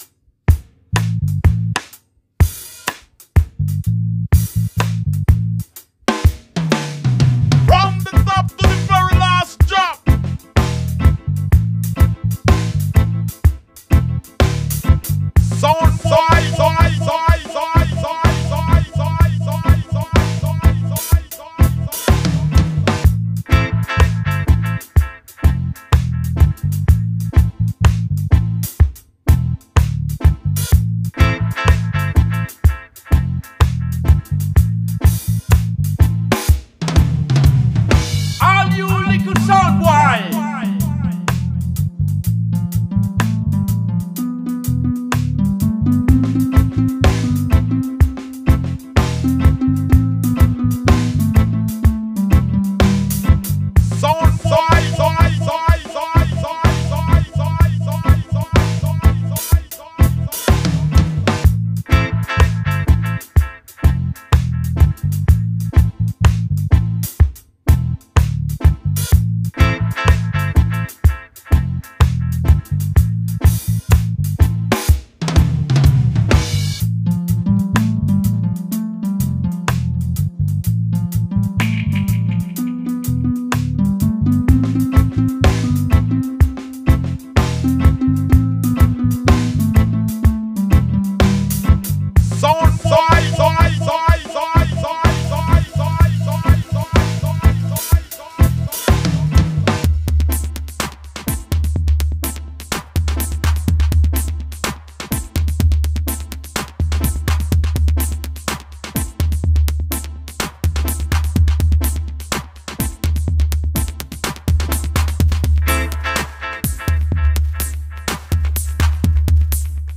Reggae Dub